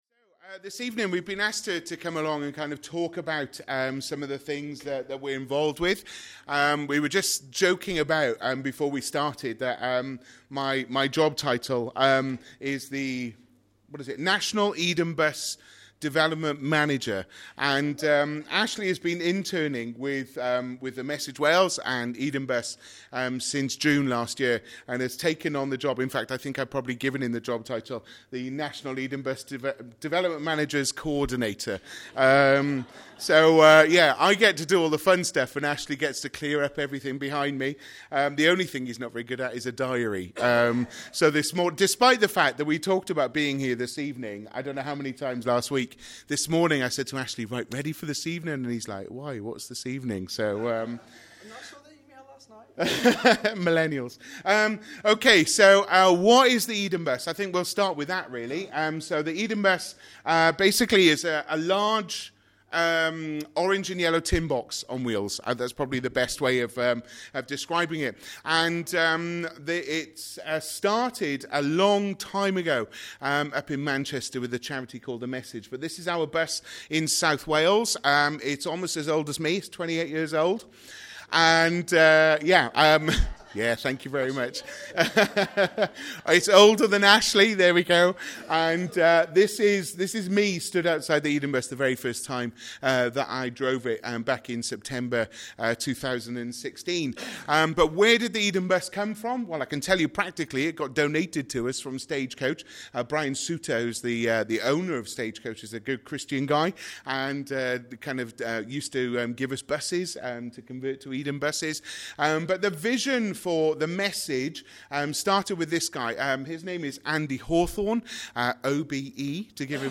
Service Type: Sunday Evening Topics: Evangelism